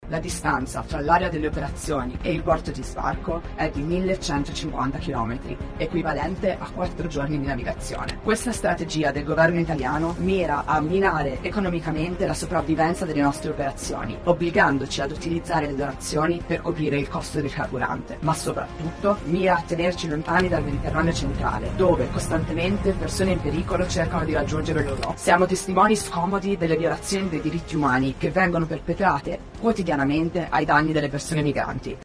E’ la distanza percorsa dalla nave Sea Watch 5 sbarcata a Marina di Carrara con a bordo 119 migranti in fuga dalla Libia, soccorsi alla vigilia di Natale. Ascoltiamo la testimonianza della ong.